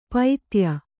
♪ paitya